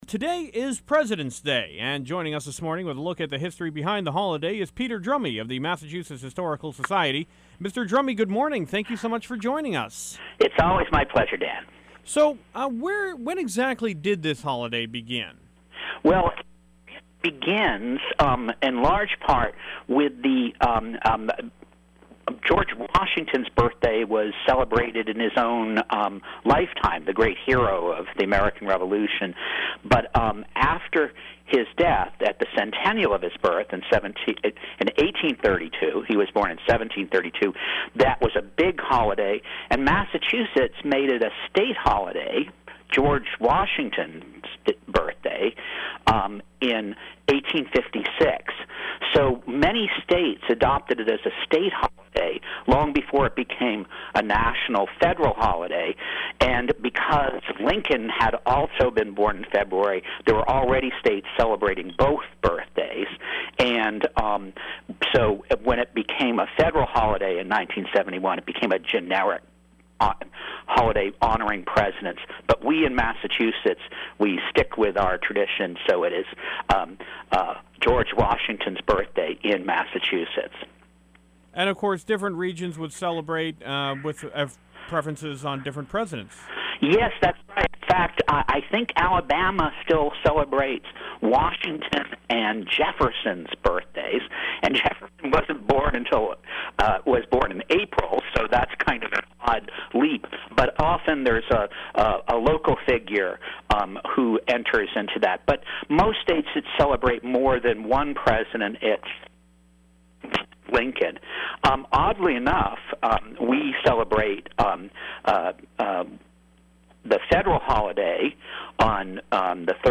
Historian Discusses the Origins of Presidents Day